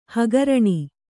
♪ hagaraṇi